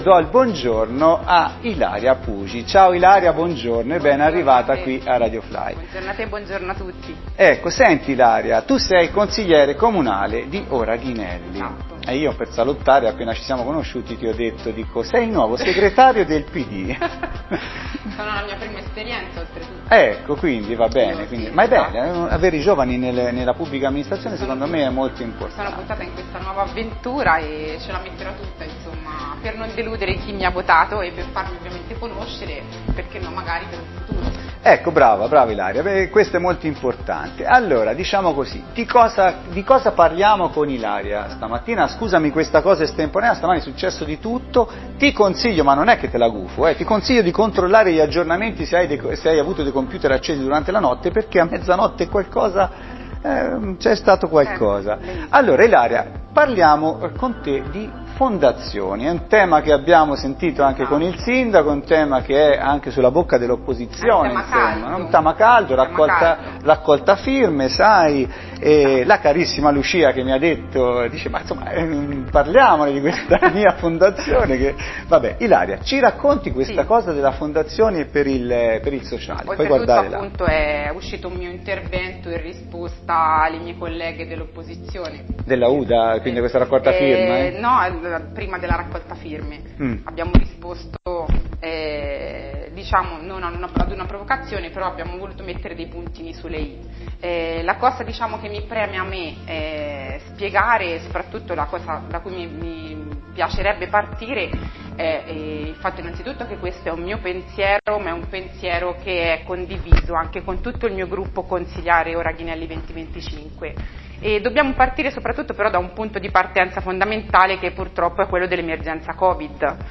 Intervento di Ilaria Pugi , consigliere della lista Ghinelli 2025, stamani in diretta su RadioFly per parlare della proposta di una Fondazione per il sociale.